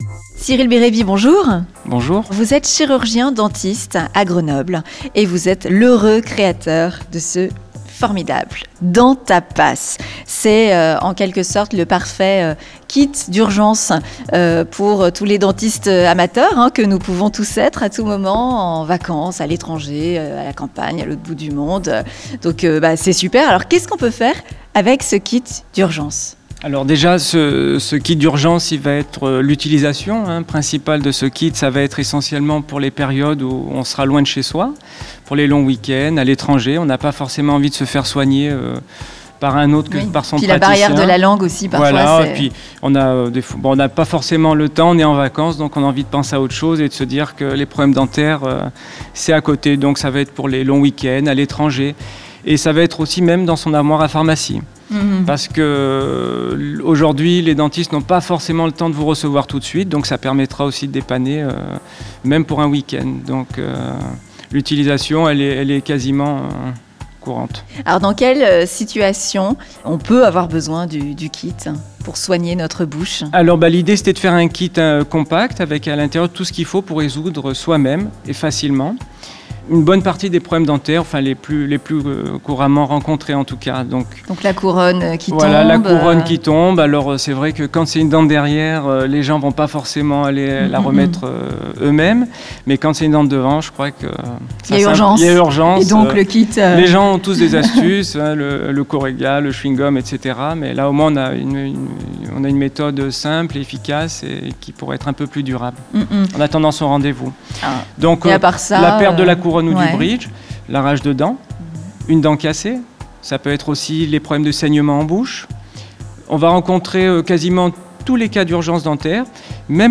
Interview-Dentapass-.mp3